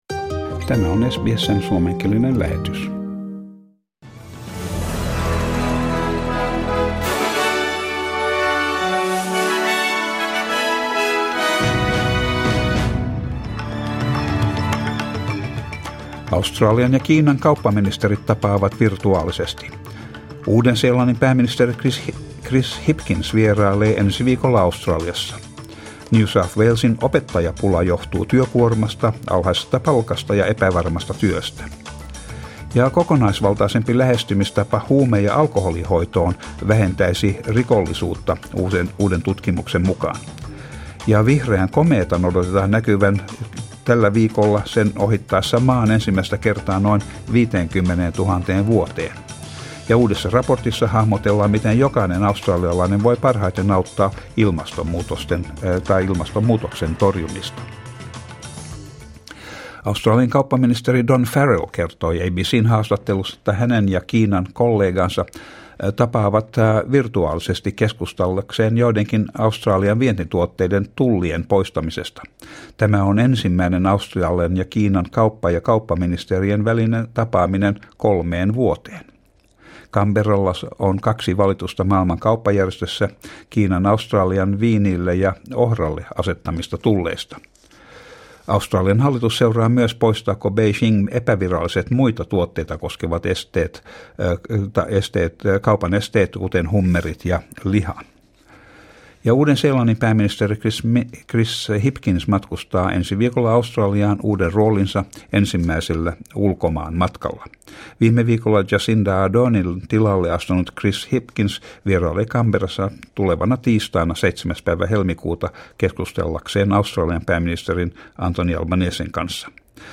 Suomenkieliset uutiset SBS Finnish